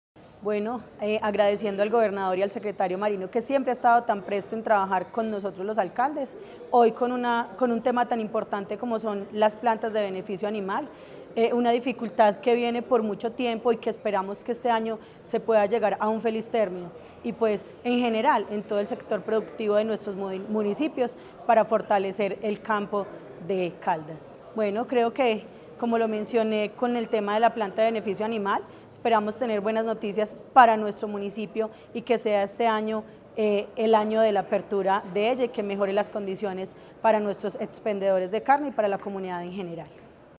Yeni Henao, alcaldesa de La Merced.